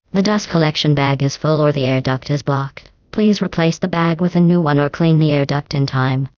glados_dreame_voice_pack_customized